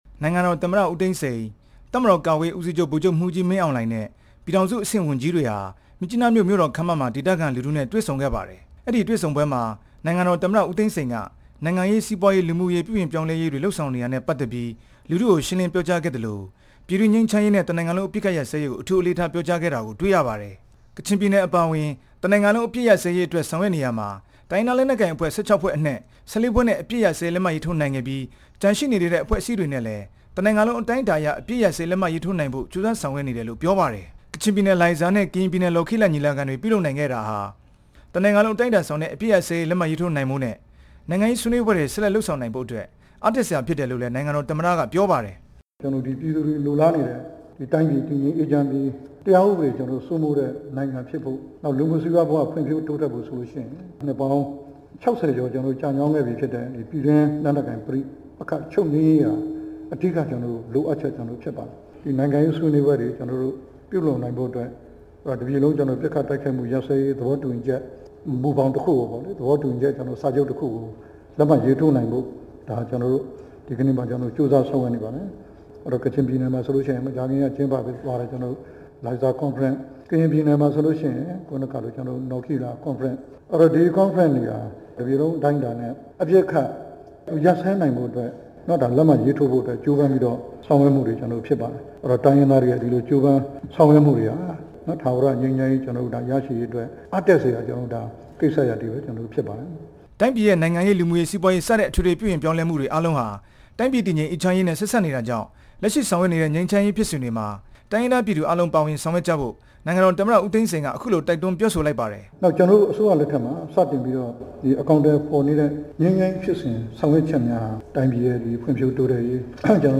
ဒီနေ့ ကချင်ပြည်နယ် မြစ်ကြီးနားမြို့ မြို့တော်ခန်းမ မှာ ဒေသခံလူထုတွေနဲ့ တွေ့ဆုံစဉ် နိုင်ငံတော်သမ္မတ က တိုင်းပြည်ဖွံ့ဖြိုးရေးနဲ့ ငြိမ်းချမ်းရေး ဆက်စပ်တဲ့ အကြောင်း ပြောကြားခဲ့တာပါ။